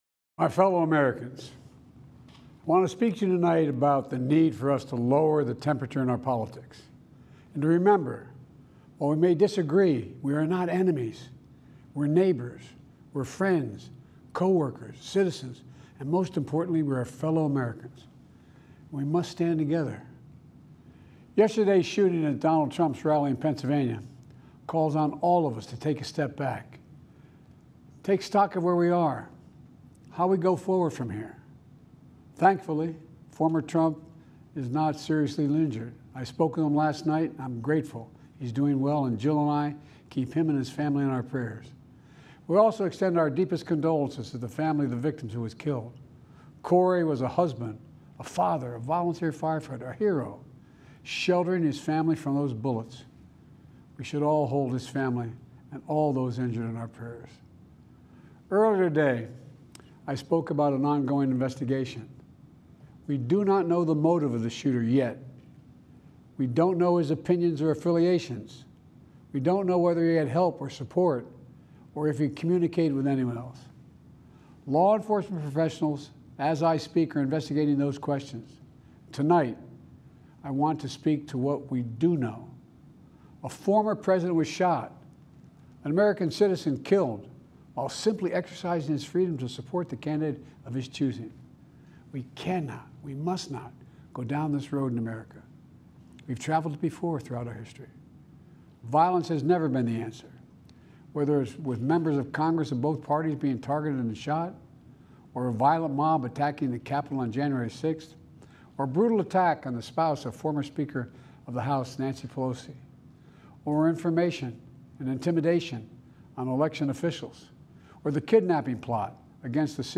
Presidential Speeches | Joe Biden Presidency